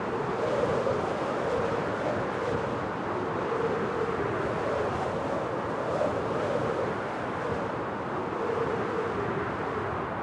Minecraft Version Minecraft Version snapshot Latest Release | Latest Snapshot snapshot / assets / minecraft / sounds / item / elytra / elytra_loop.ogg Compare With Compare With Latest Release | Latest Snapshot
elytra_loop.ogg